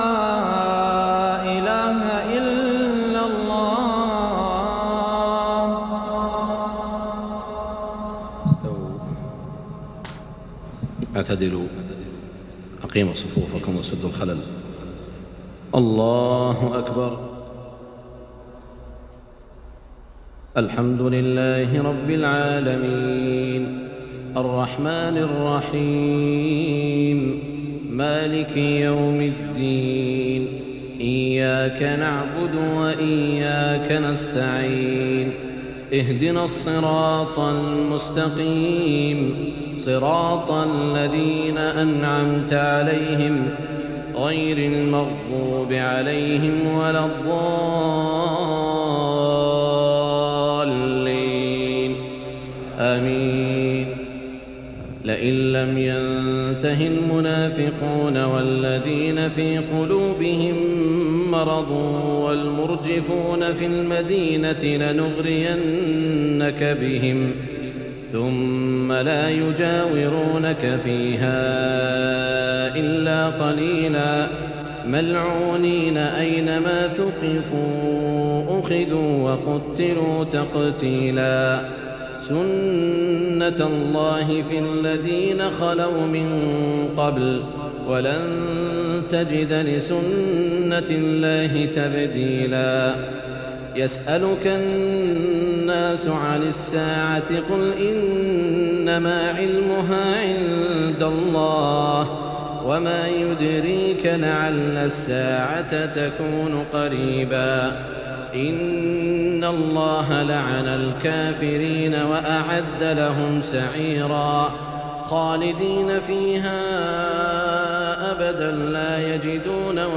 صلاة العشاء 1422هـ من سورة الأحزاب جامع العودة > تلاوات الشيخ سعود الشريم خارج الحرم > تلاوات و جهود الشيخ سعود الشريم > المزيد - تلاوات الحرمين